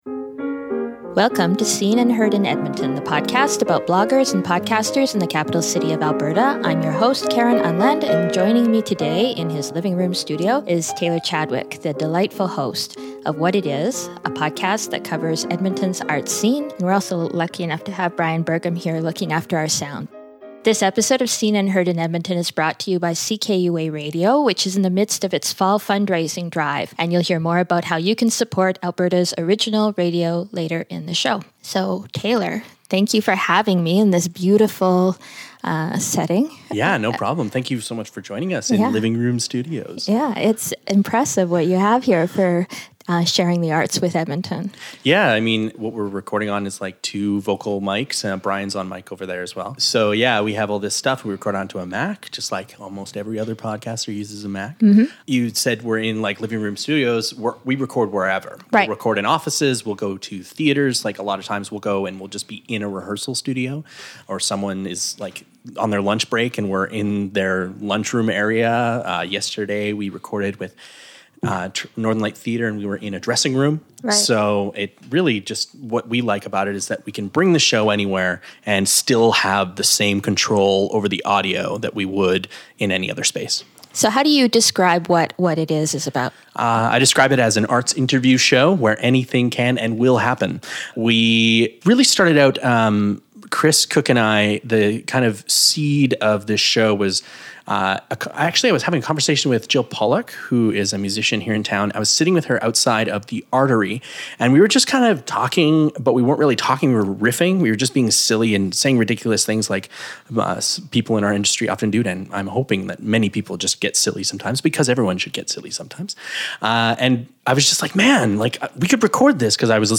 Here’s our conversation: